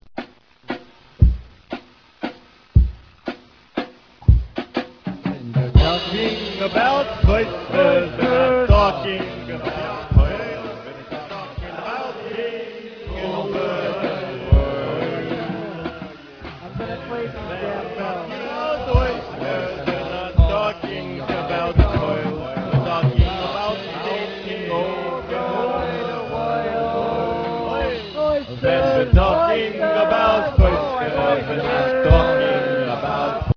the music is called "tripgrass"
He started playing the banjo when he was 17.